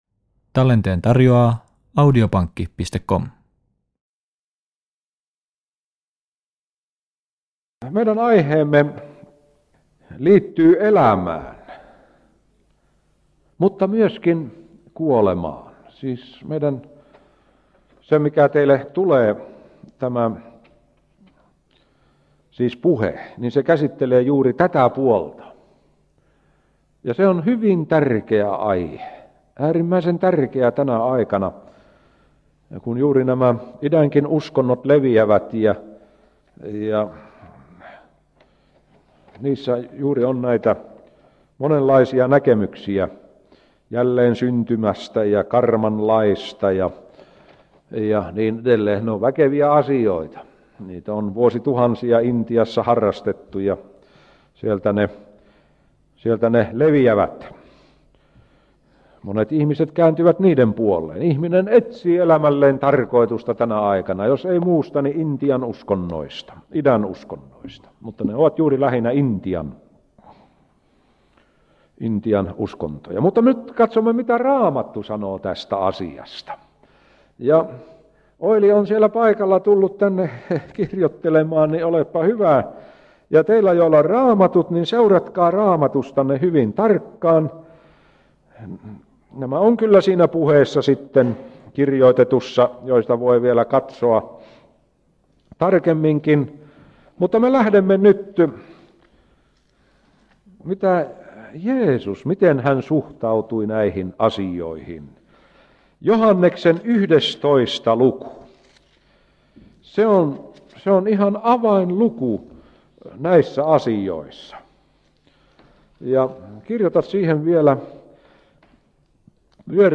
Tämä on 17 -osainen puhesarja, jossa tutustutaan Raamatun tärkeimpiin opetuksiin.